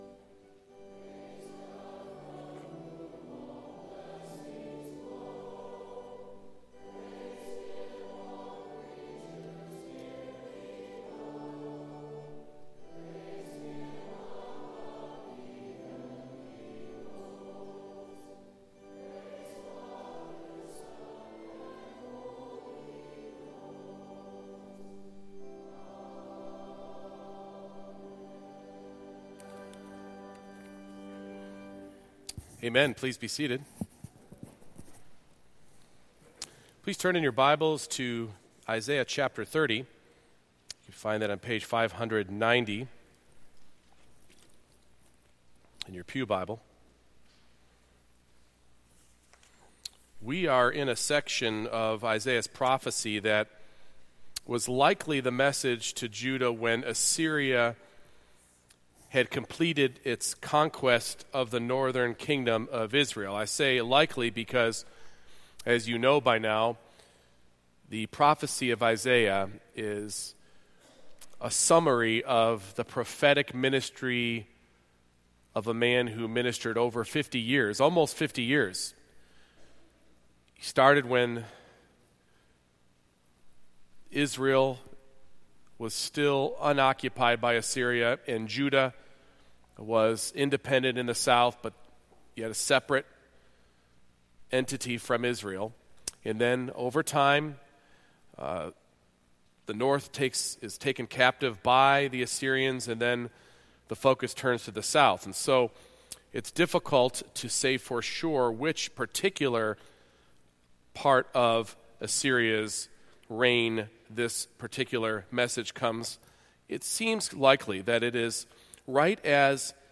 Isaiah 30:1-33 Service Type: Morning Worship God graciously cultivates the gift of faith